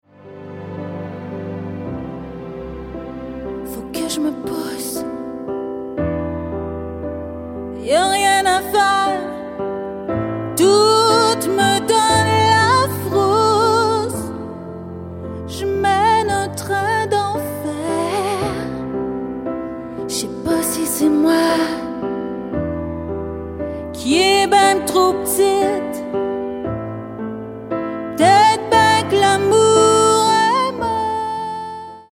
2010 Nos stars chantent le blues à Montréal